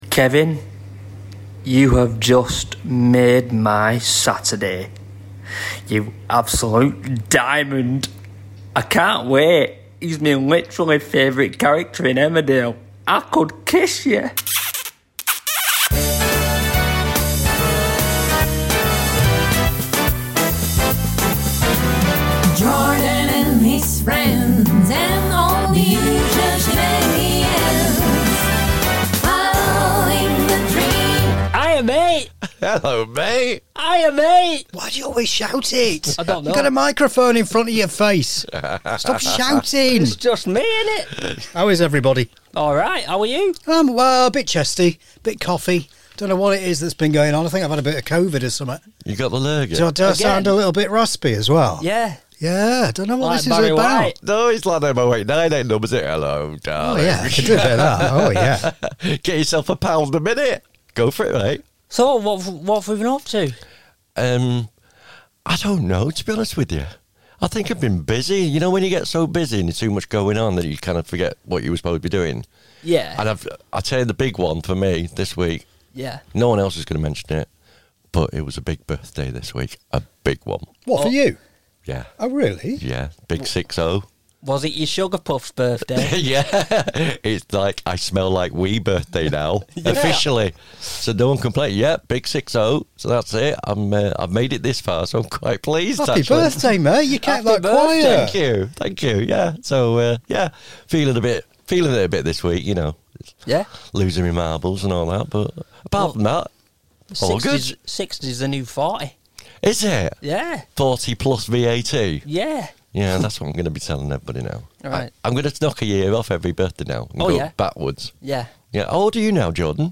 A BIG interview and a lovely voicemail.
A voicemail comes in mid-podcast and the usual shenanigans occurs.